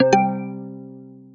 notification.a454816b5107f49c62d2.mp3